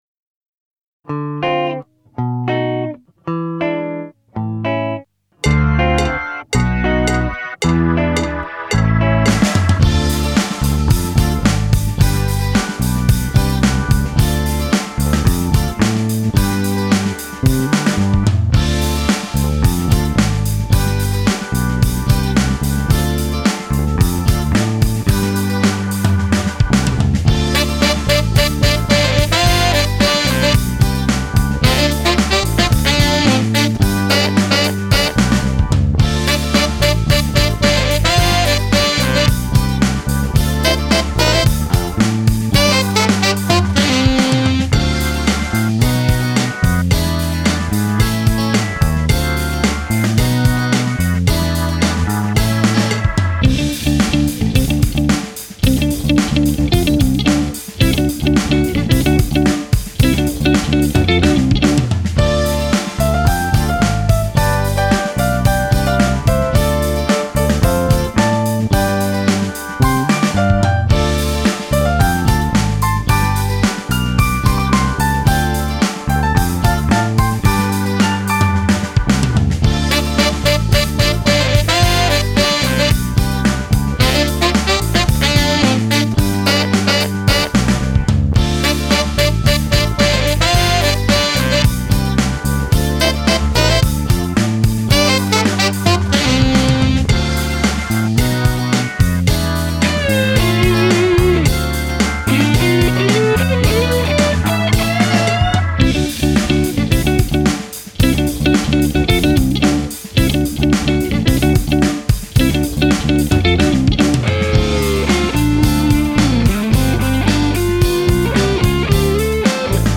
I also built the drum track using the Logic Pro drummer sideman. Guitars played included my SG, Tele and Silver Sky SE through a Tonex pedal or a UAD Dream ’65 pedal.